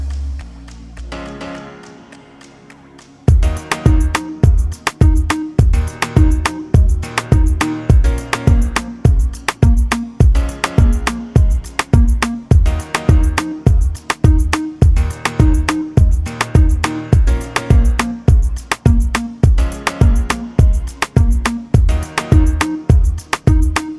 for solo male R'n'B / Hip Hop 2:54 Buy £1.50